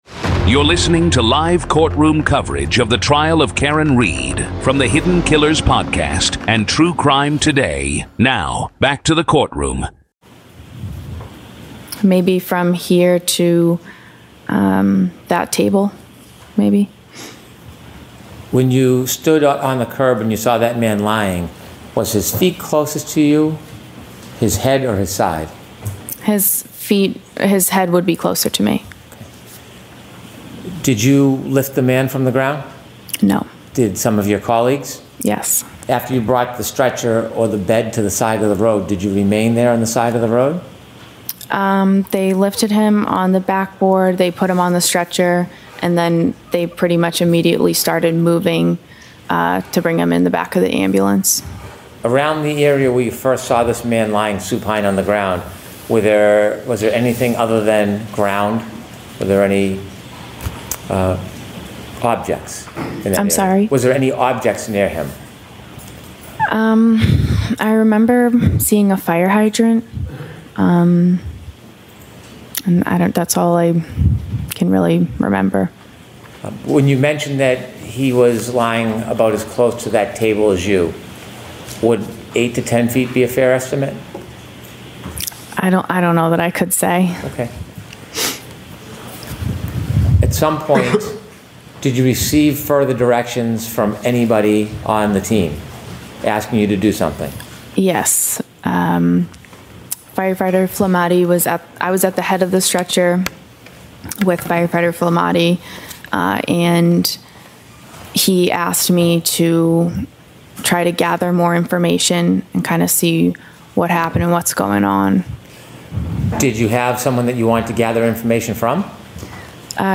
This is audio from the courtroom